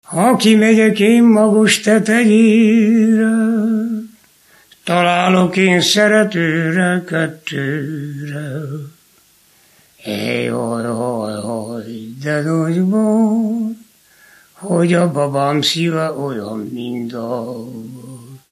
Erdély - Csík vm. - Csíkjenőfalva
ének
Műfaj: Asztali nóta
Stílus: 6. Duda-kanász mulattató stílus